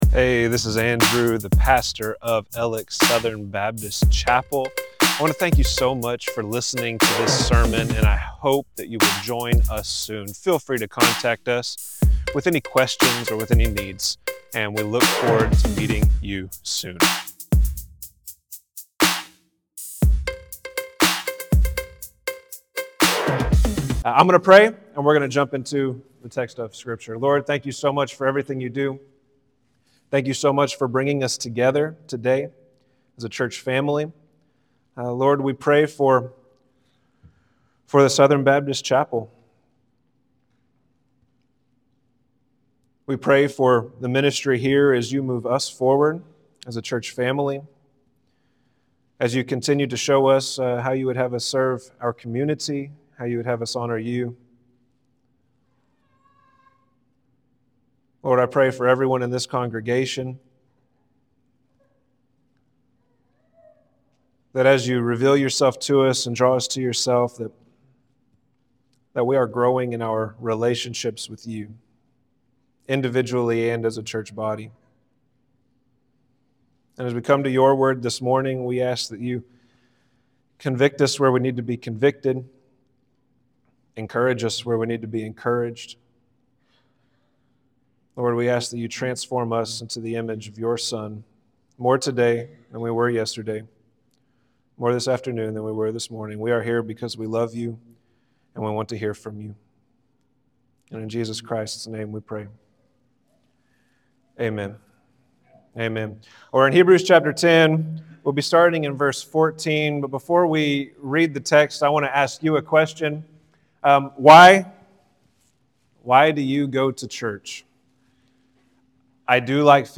Alex Southern Baptist Chapel Sermons Church is Worship